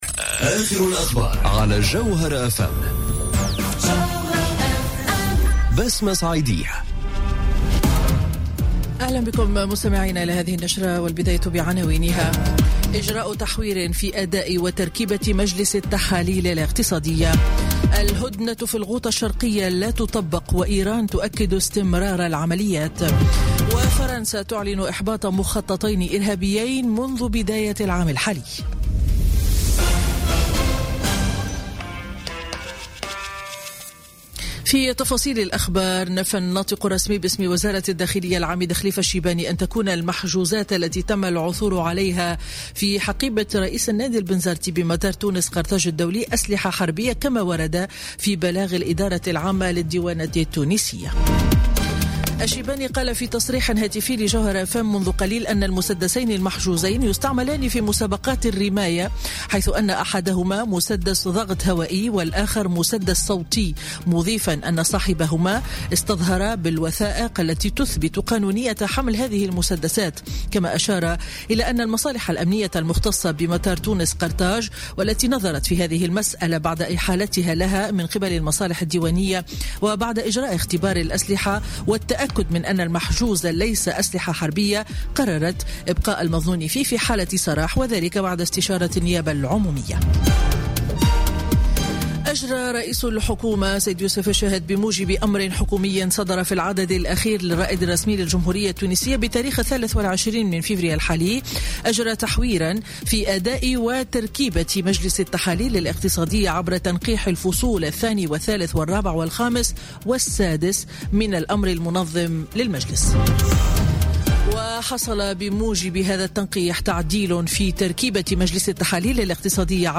نشرة أخبار منتصف النهار ليوم الأحد 25 فيفري 2018